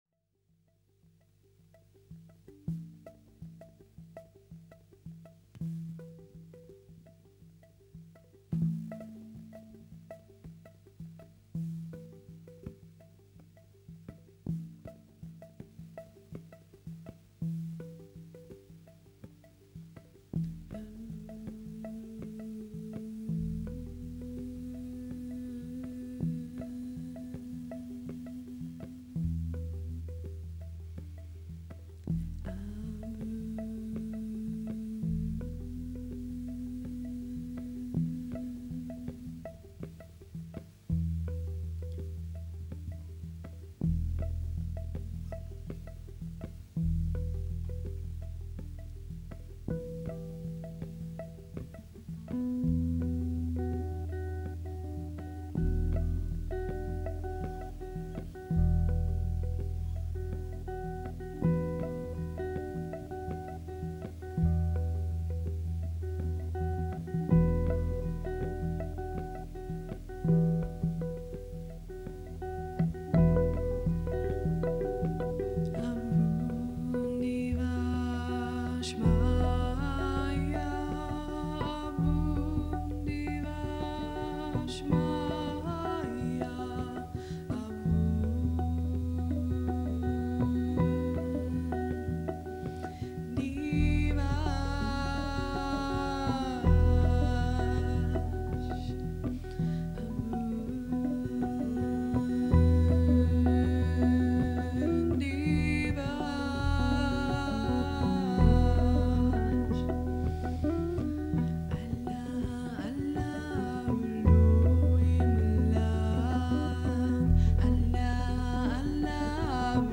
ambientní etno-jazz s texty aramejského otčenáše